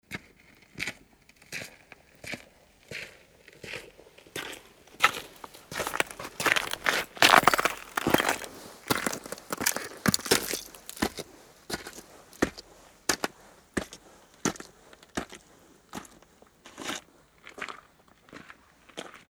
Gemafreie Sounds: Schritte
mf_SE-4721-steps_in_snow_4.mp3